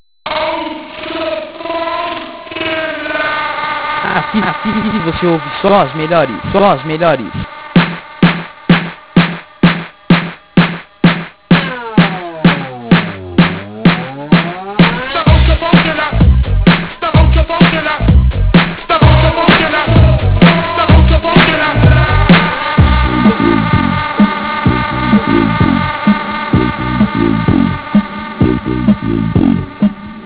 Aqui Algumas De Nossas Vinhetas e Chamadas
Todas Produzidas Pelos Dj's Da Rádio